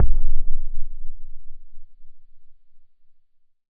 explosion_far_distant_04.wav